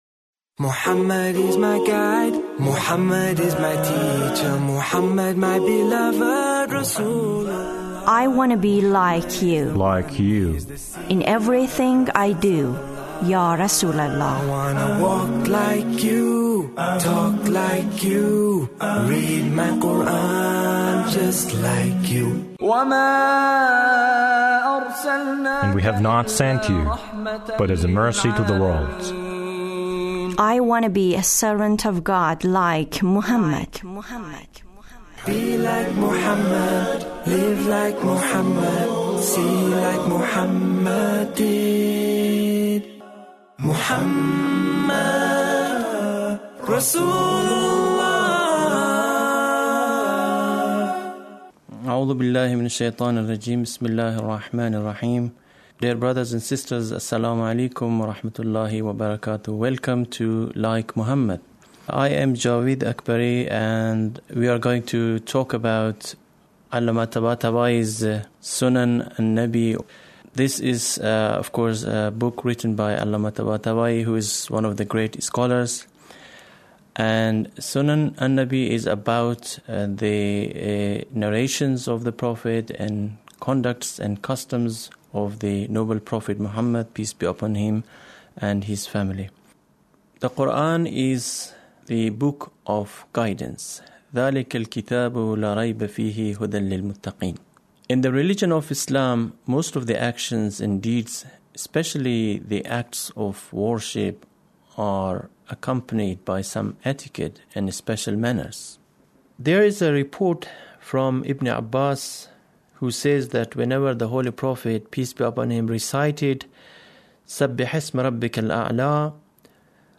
SUNNAH OF THE PROPHET(Reciting Quran)